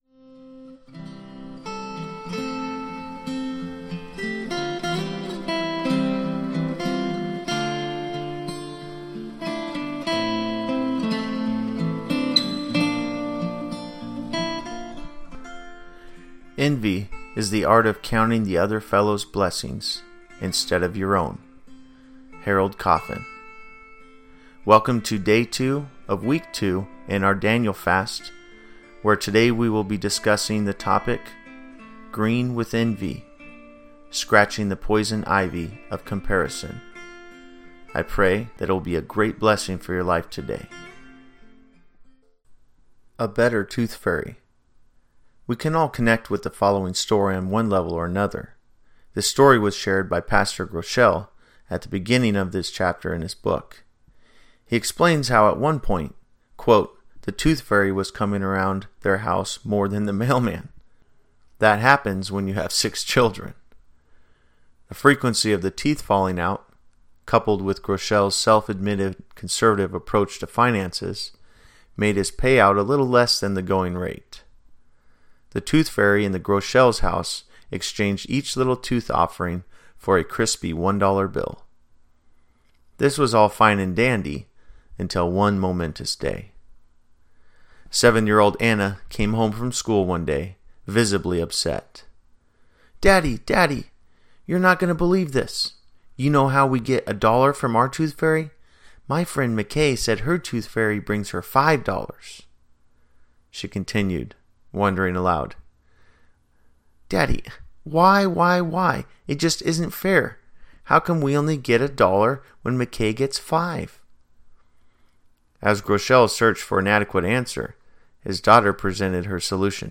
January 9, 2019 Green With Envy: Scratching the Poison Ivy of Comparison Below you will find an audio of the devotion being read for you, followed by the written devotion.